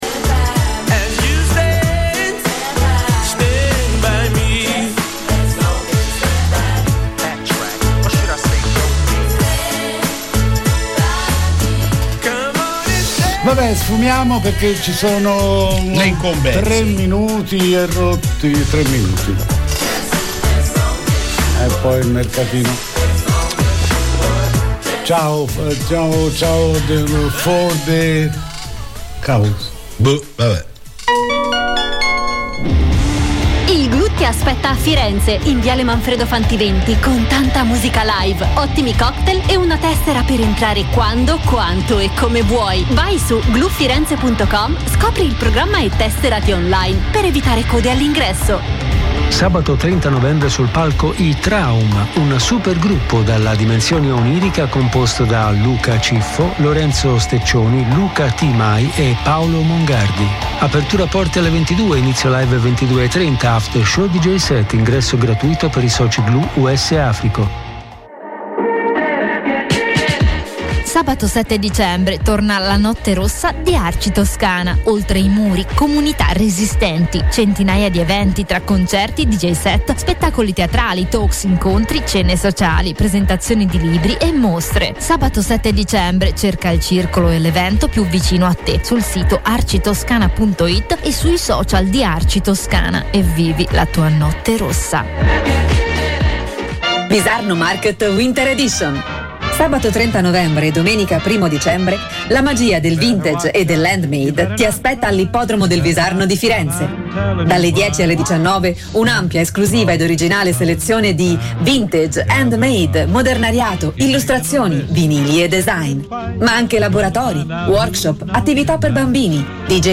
Vendo compro e scambio in diretta su Controradio